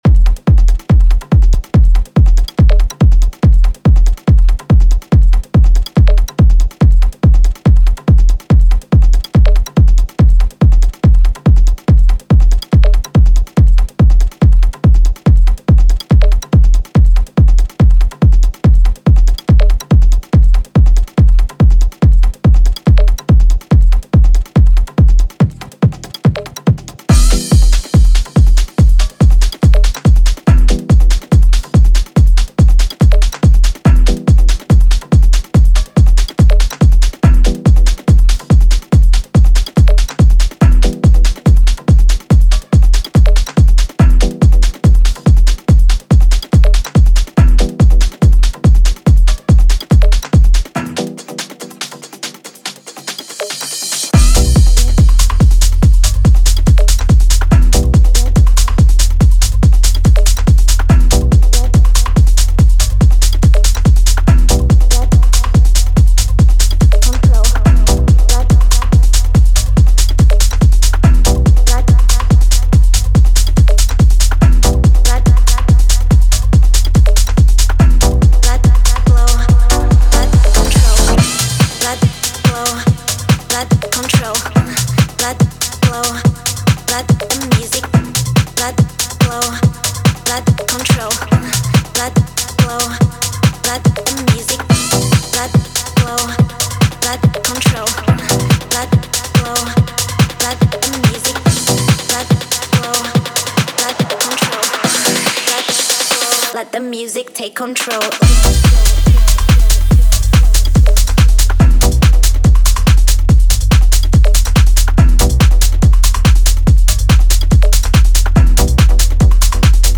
Für den Start haben wir dir vier Hardgroove-Tracks bereitgestellt.